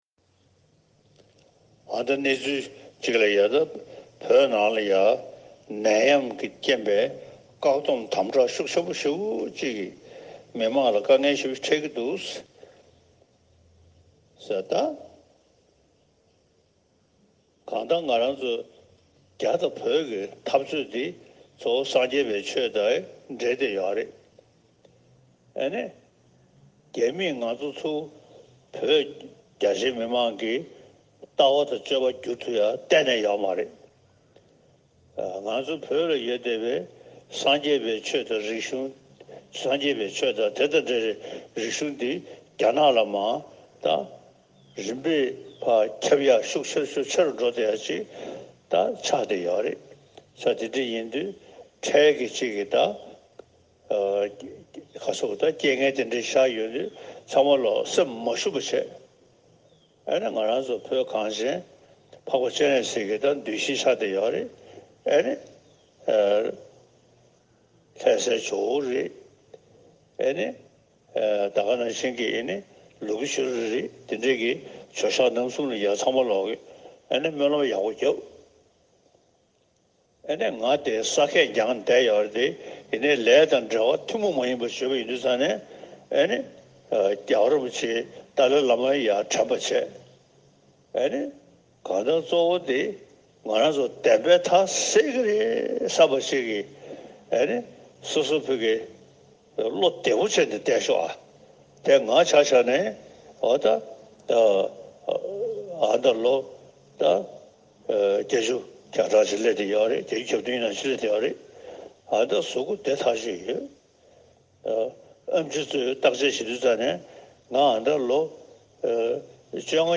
HH advise Tibetan in Tibet over Covid19 (1).mp3